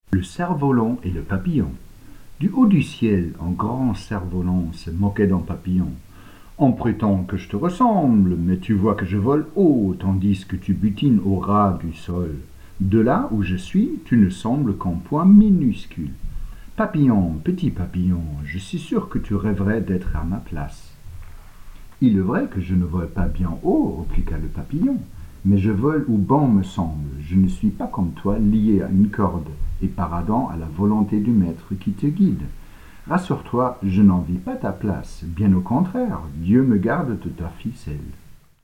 Voix off
Narrateur